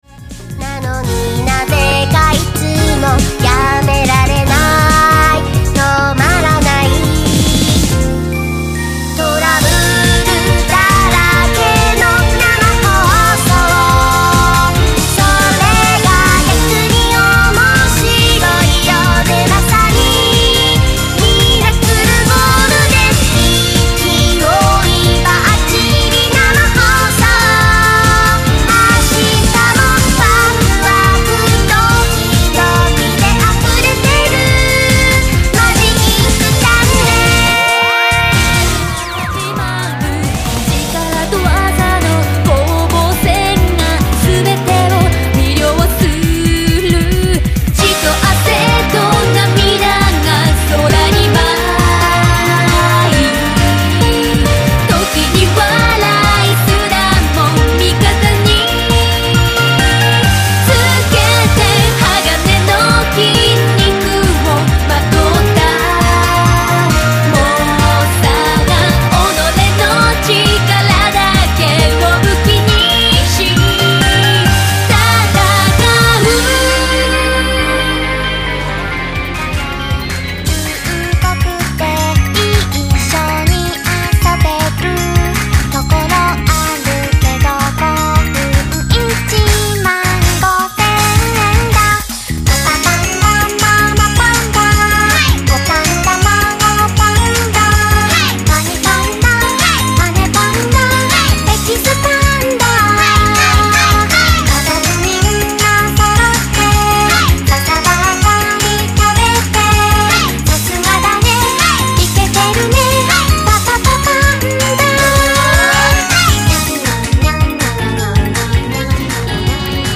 ■全曲クロスフェード　⇒
※実際の曲順と同じ順番で収録されています。